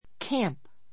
kǽmp